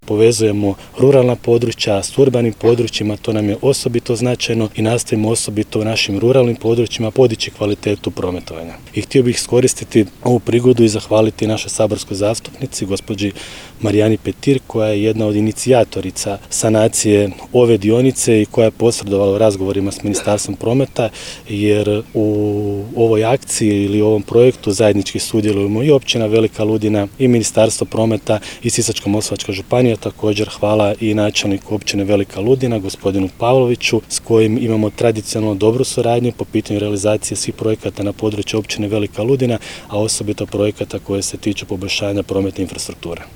FOTO | Svečano otvorena cesta Mala Ludina-Kompator-Mustafina Klada
Načelnik Općine Velika Ludina, Dražen Pavlović izrazio je veliko zadovoljstvo ovim projektom